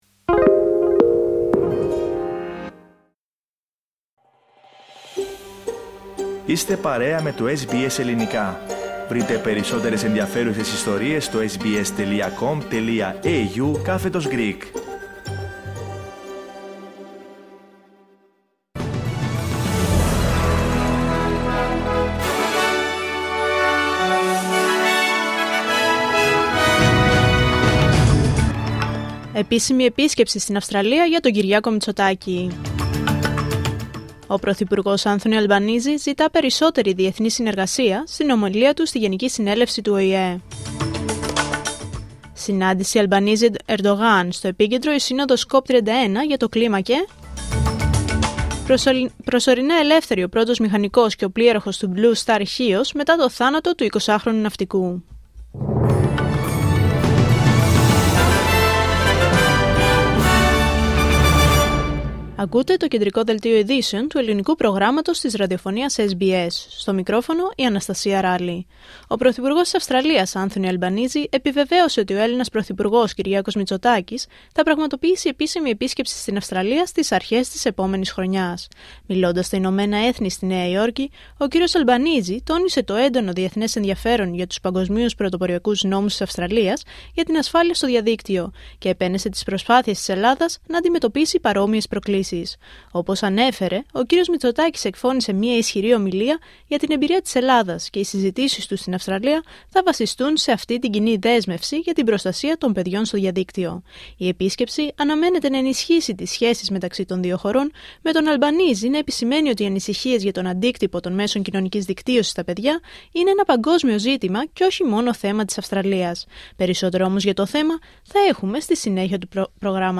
Δελτίο Ειδήσεων Πέμπτη 25 Σεπτεμβρίου 2025